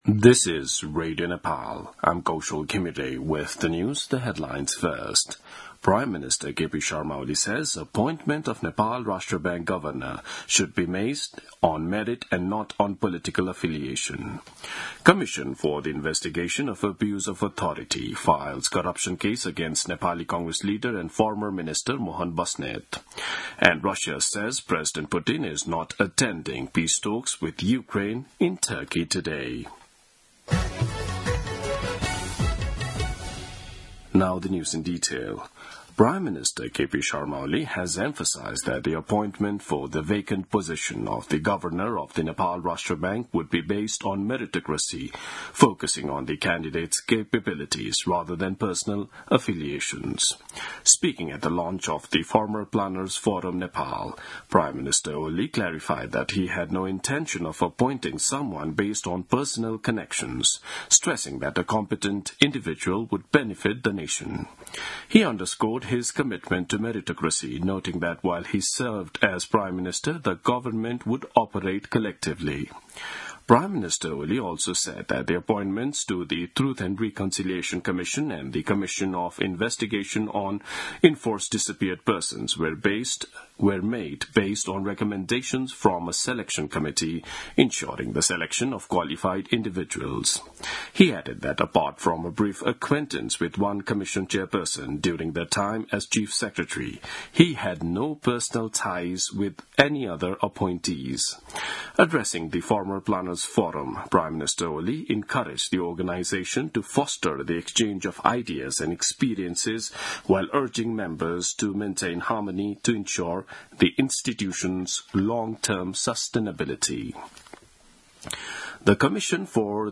दिउँसो २ बजेको अङ्ग्रेजी समाचार : १ जेठ , २०८२
2-pm-news-1-2.mp3